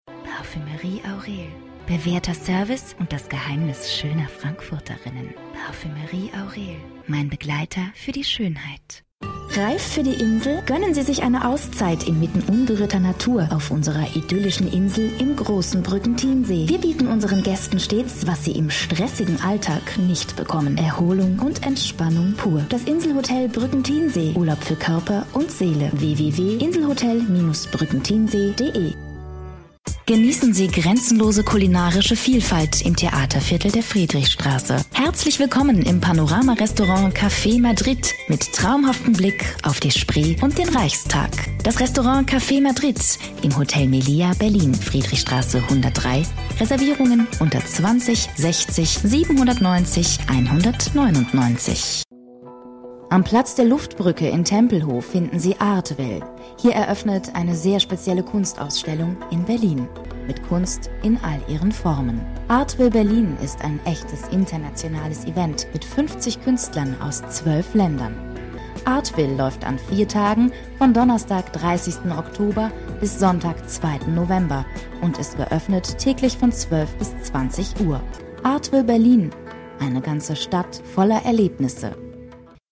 Sprechprobe: eLearning (Muttersprache):
german female voice over artist